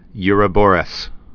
(yrə-bôrəs)